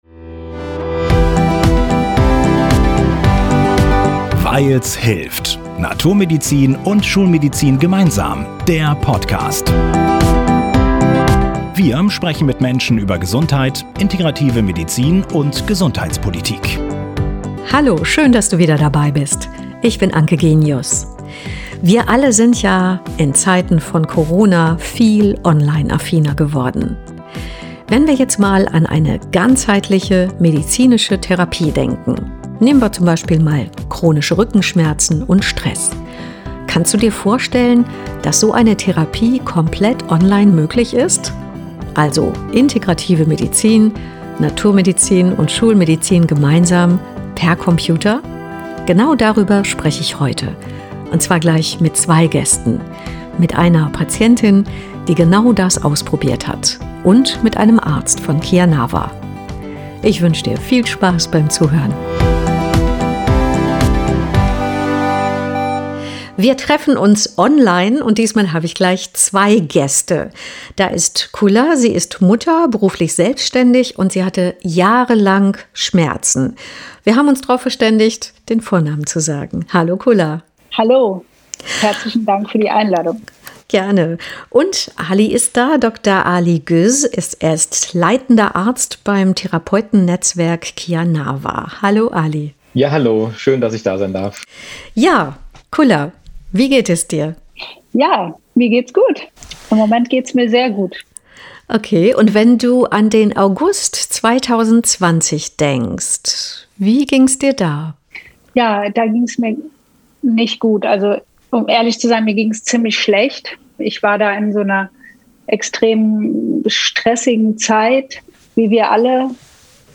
Mehr zu unseren Interviewpartnern und weitere Informationen